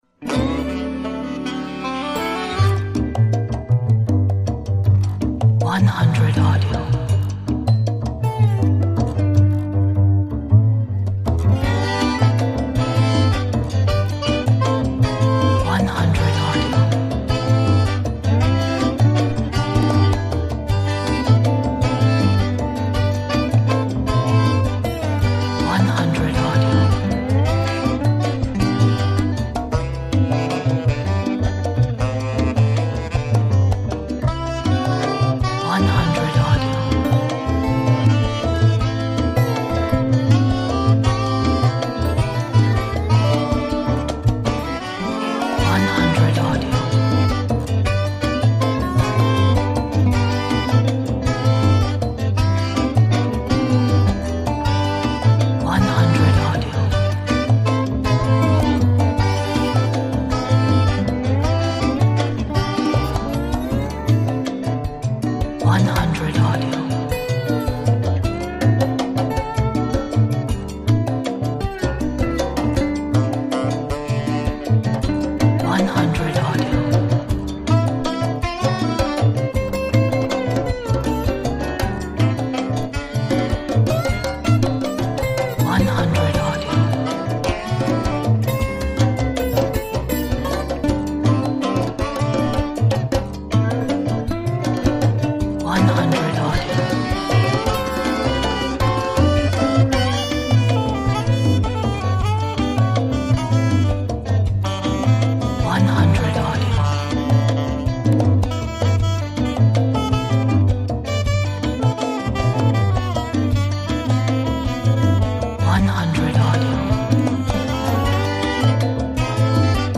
Joyful music with a fragrance of Africa.